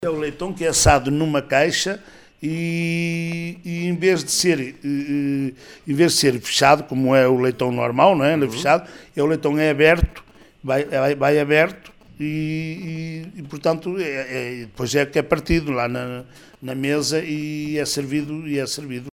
“É assado numa caixa e é partido na mesa onde é servido”, explicou à Rádio Vale do Minho o presidente da Junta da União de Freguesias de São Julião e Silva, Manuel Alberto Barros.